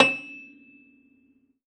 53x-pno13-E5.wav